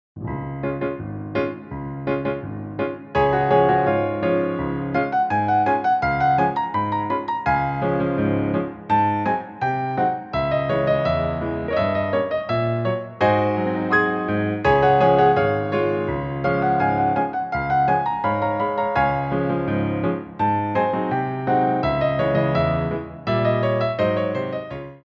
Glissés
4/4 (16x8) + Stop Time